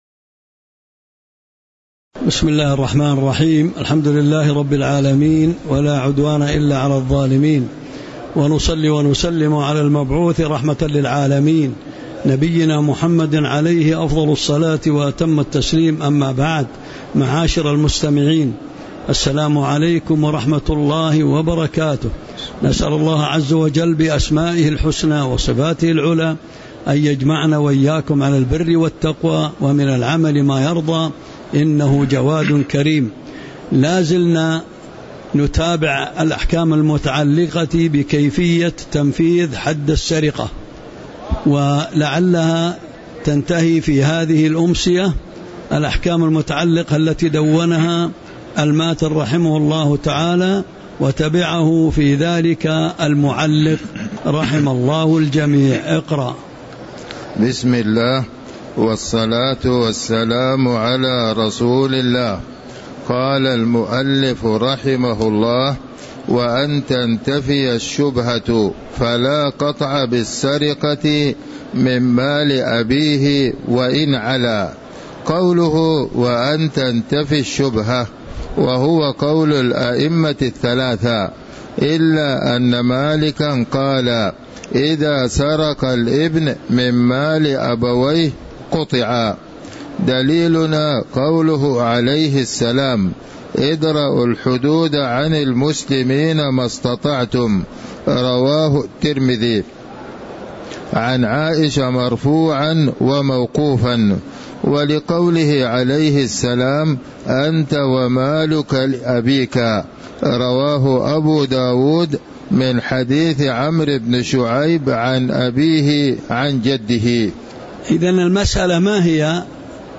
تاريخ النشر ٢٧ صفر ١٤٤٥ هـ المكان: المسجد النبوي الشيخ